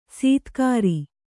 ♪ sītkāri